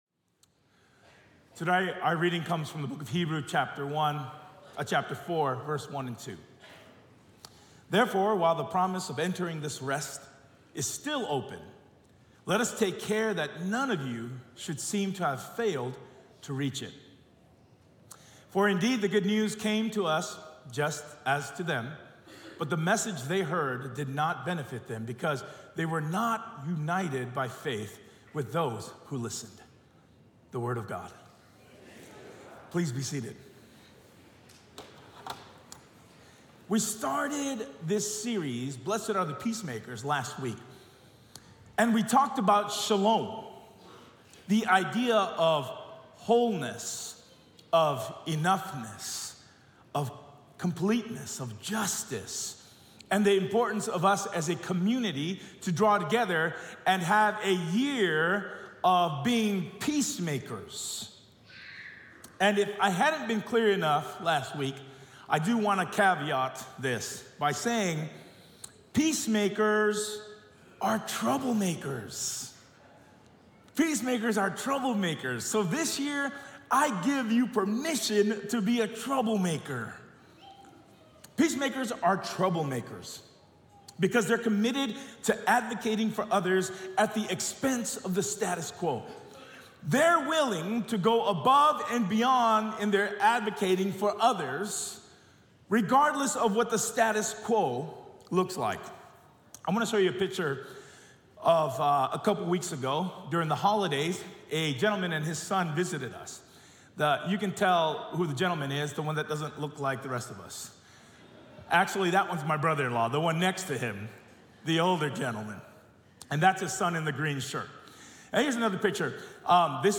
Sermon Series Archive | La Sierra University Church